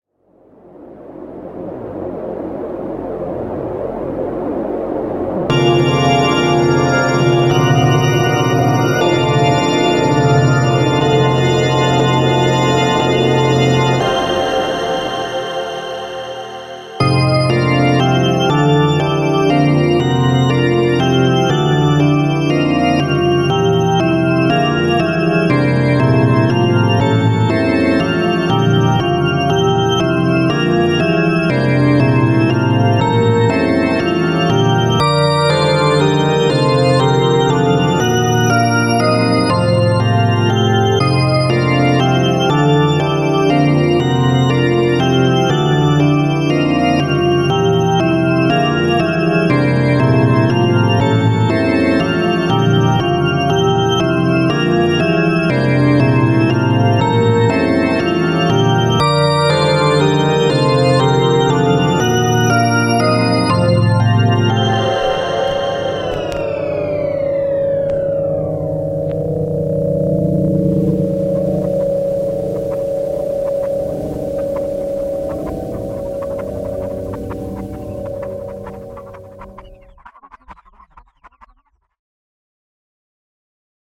There are many really good free organs and synths out there but if you choose nothing else download the Surge VST -- it’s a surprisingly good synth and I used it on my organ music heard above.